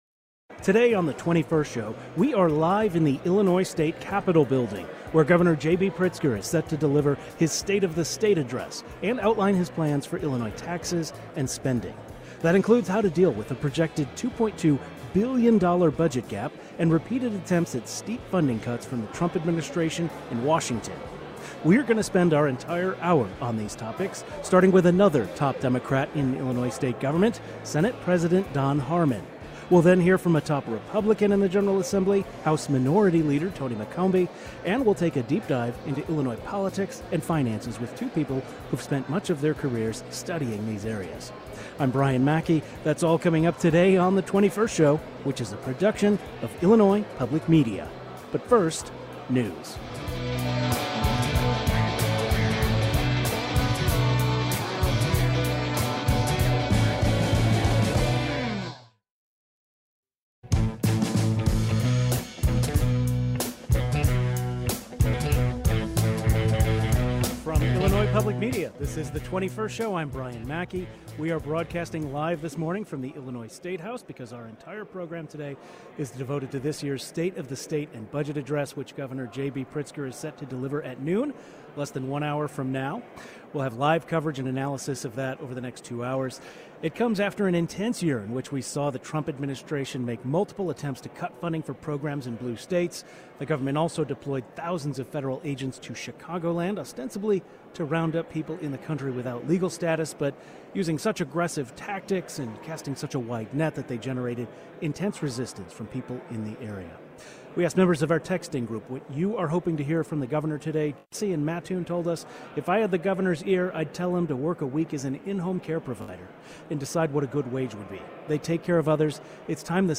Leaders in the state legislature and policy experts share their analysis and expectations of this year's budget.